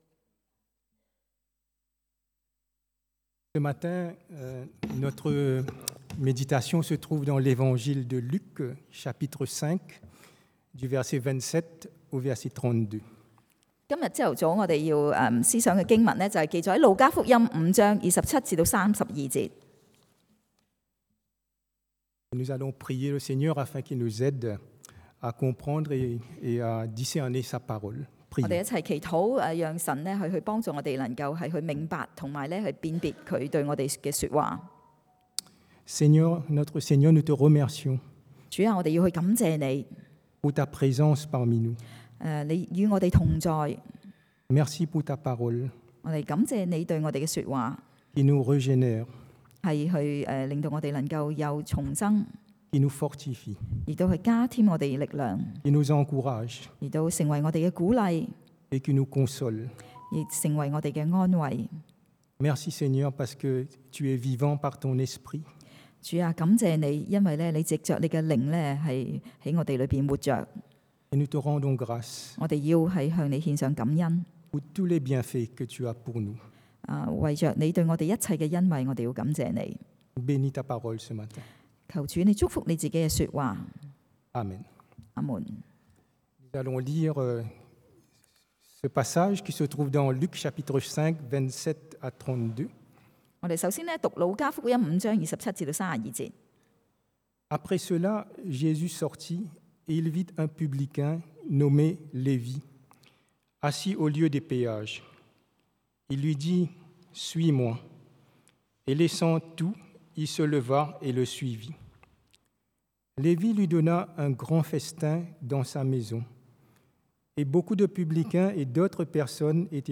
Passage: Luc 路加福音 5:27-32 Type De Service: Predication du dimanche